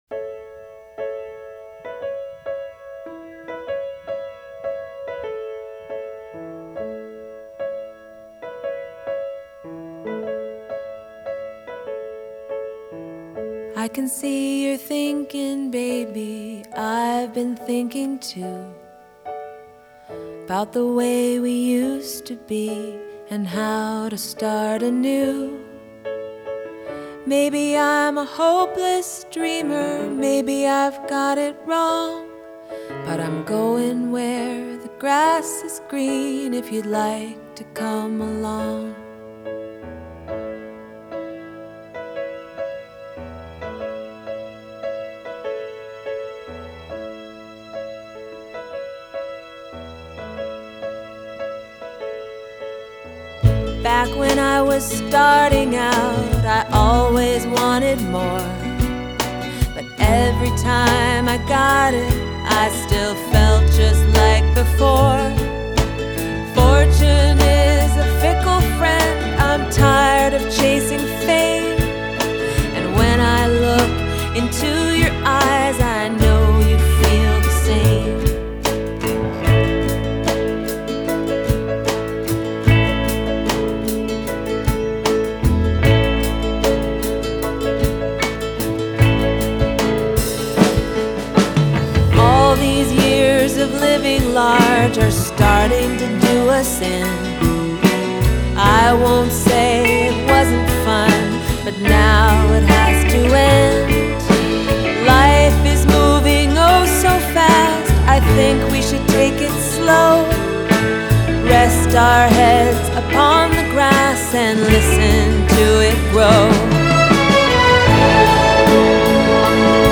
Genre: Jazz,Latin